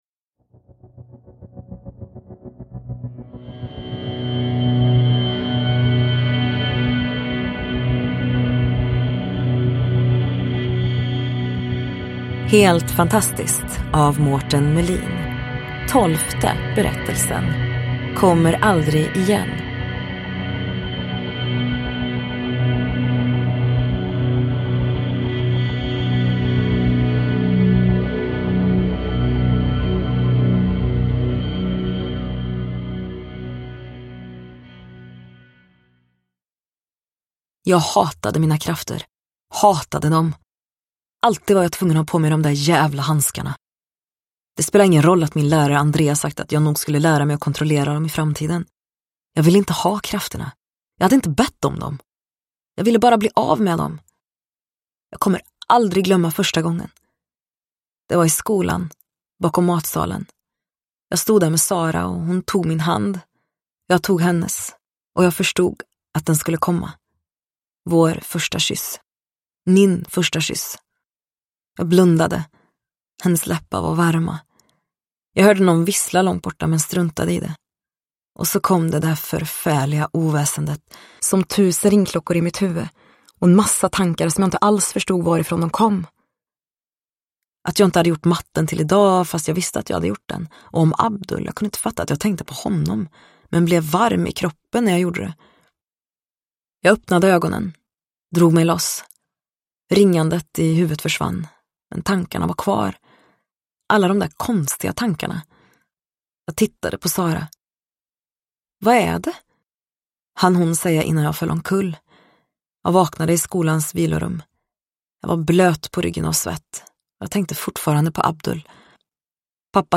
Kommer aldrig igen : en novell ur samlingen Helt fantastiskt – Ljudbok – Laddas ner